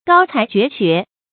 高才绝学 gāo cái jué xué
高才绝学发音